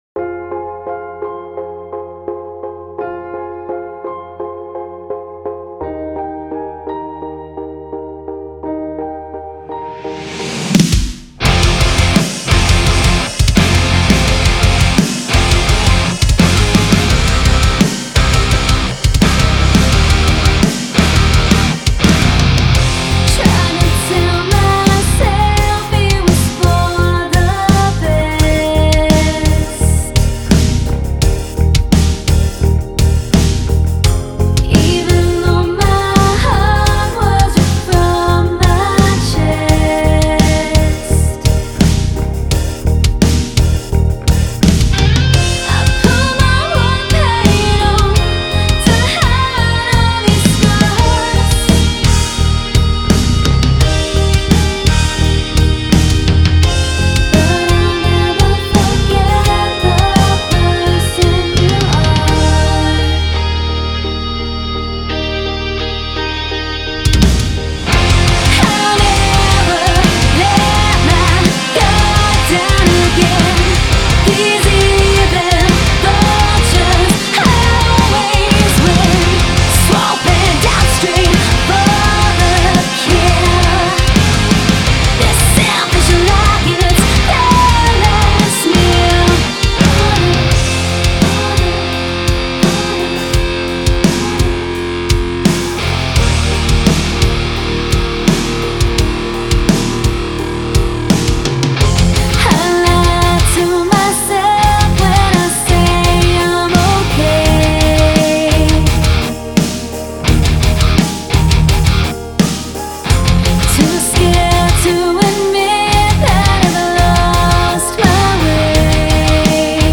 آلترناتیو راک